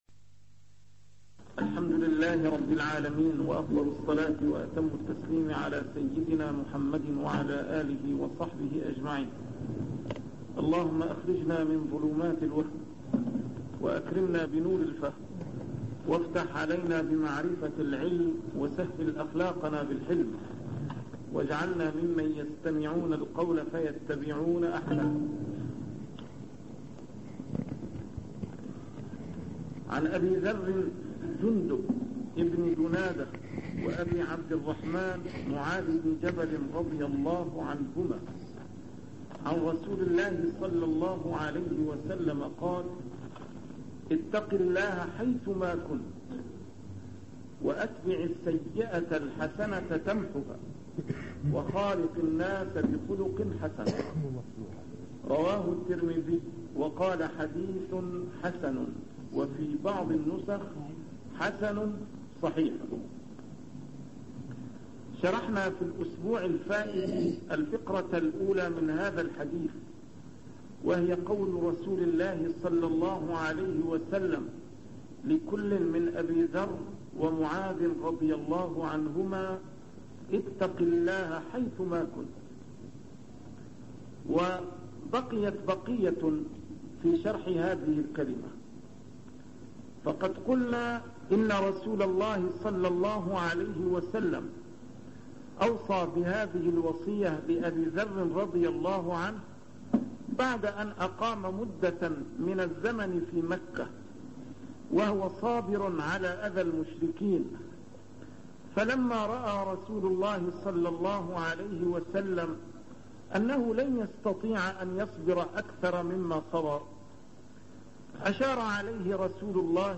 A MARTYR SCHOLAR: IMAM MUHAMMAD SAEED RAMADAN AL-BOUTI - الدروس العلمية - شرح الأحاديث الأربعين النووية - تتمة شرح الحديث الثامن عشر: حديث أبي ذر (اتق الله حيثما كنت) 58